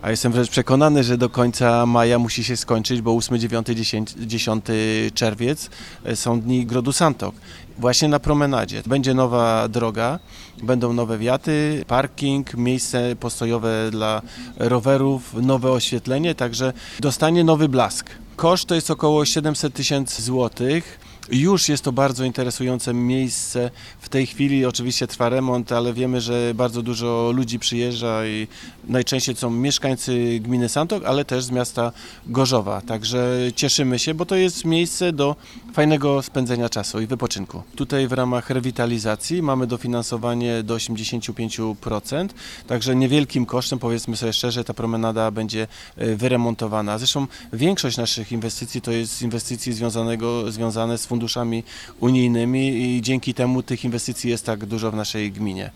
– Termin zakończenia prac określony jest na koniec maja – mówi wójt Józef Ludniewski: